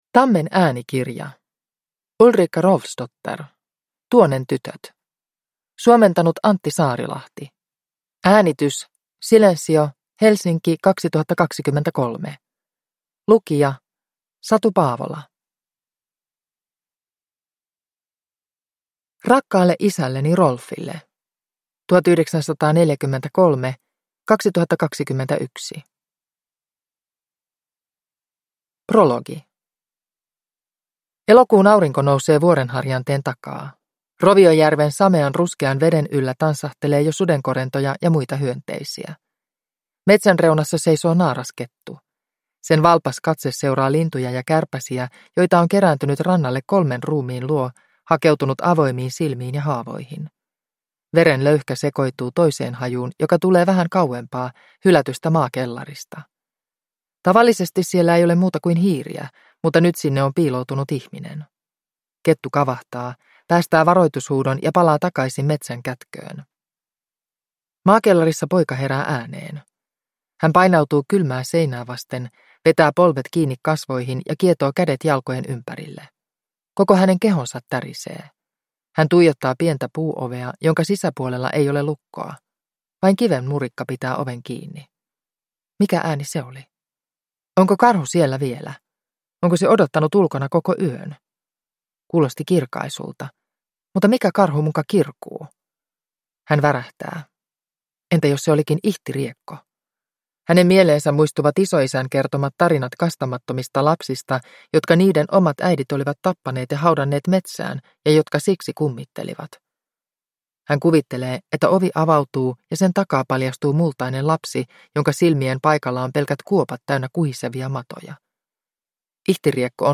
Tuonen tytöt – Ljudbok – Laddas ner